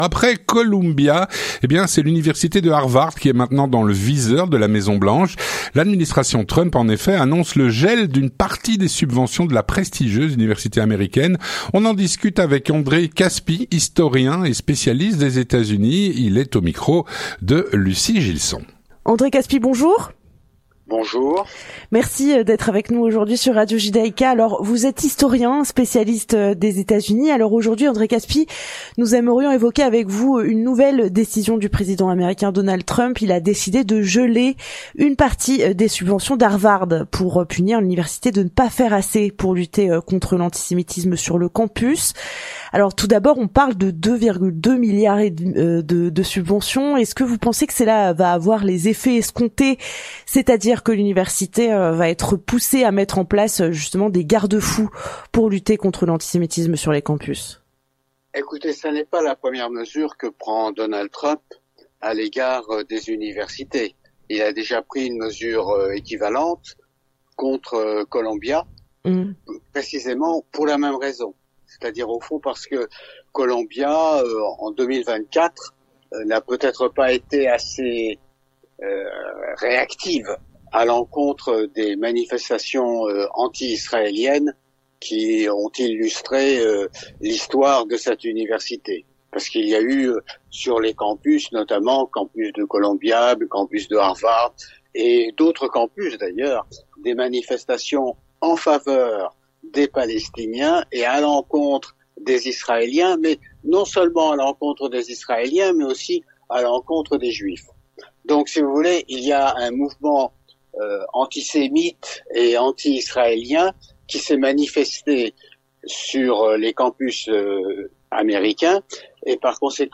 Nous en discutons avec André Kaspi, historien spécialiste des Etats-Unis.